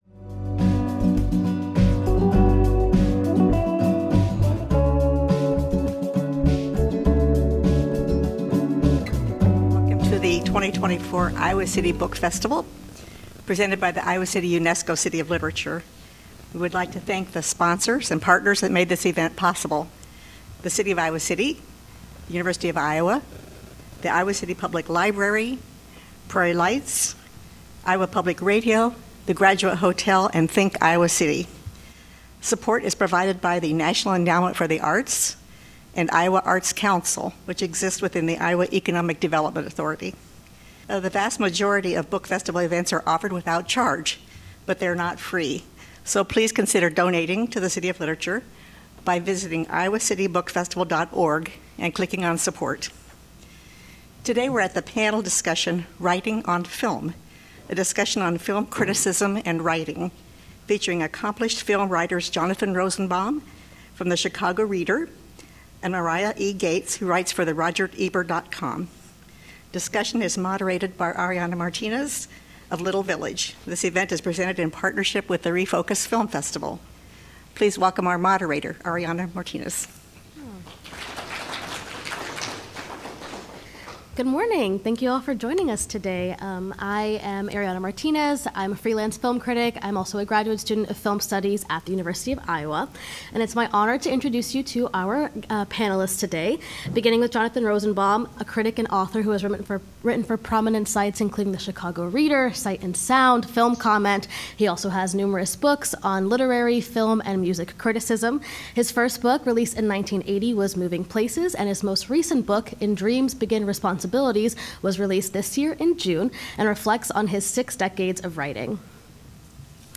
Iowa City UNESCO City of Literature presents a panel discussion from its 2024 Iowa City Book Festival.